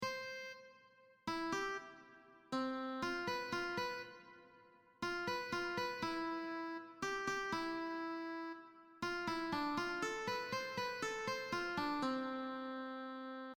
textual bahnhofplatz Im zugeordneten Tonraum werden für die drei Gruppen «Melodien» komponiert.
suchenden Ortsunkundigen sind nervös und sprunghaft.
klingender_bahnhofplatz_suchen.mp3